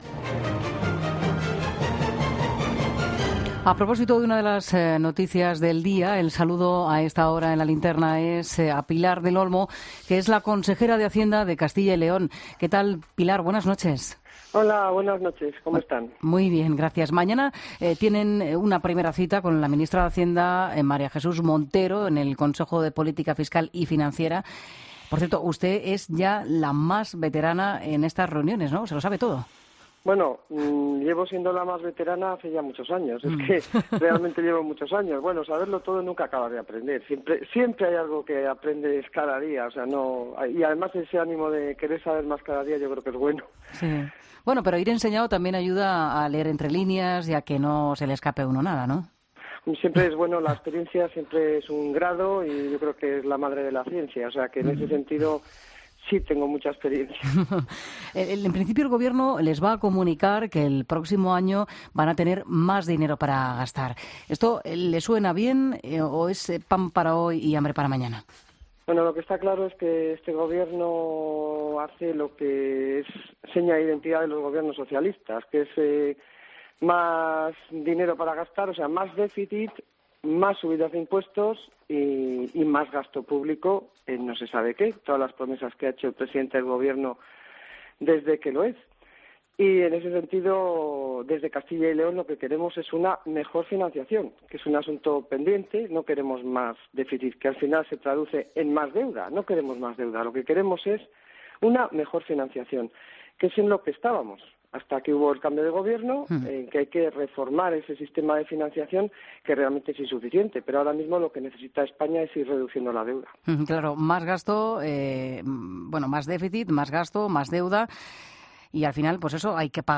Pilar del Olmo, Consejera de Hacienda de Castilla y León, en el 'Equipo Económico' de 'La Linterna', miércoles 18 de julio de 2018